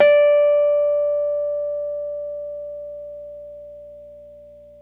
RHODES CL0FR.wav